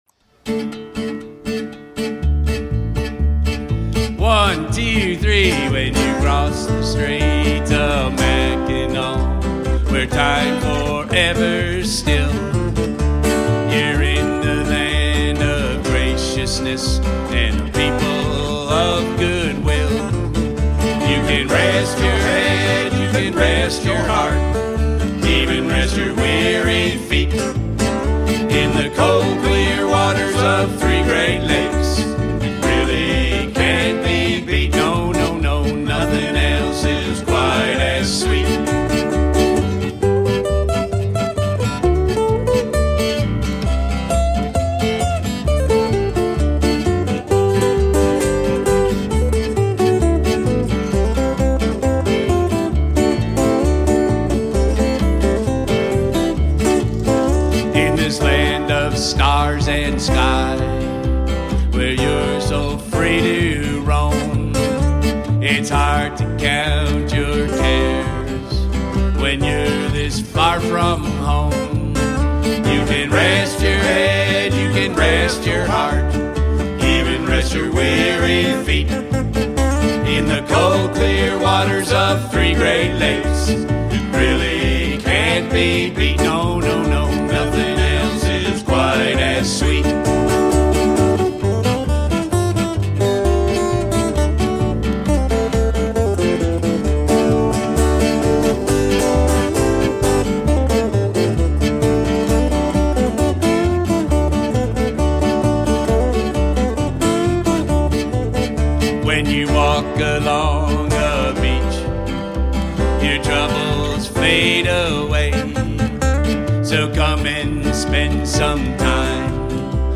a collection of original vocal songs
Recorded live on the road during summers 2010 and 2011.